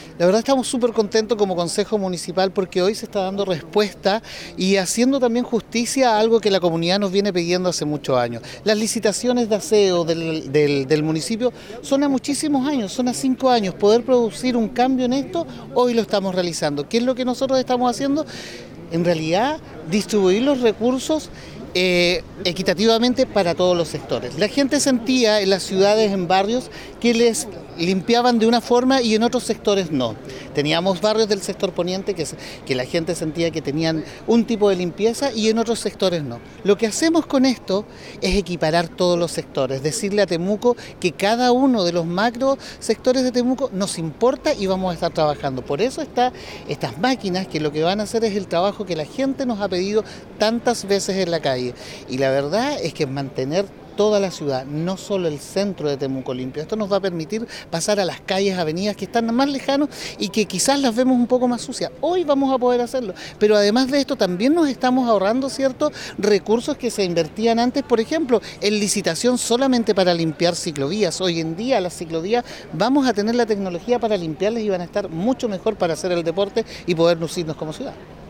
Esteban-Barriga-concejal-Temuco.mp3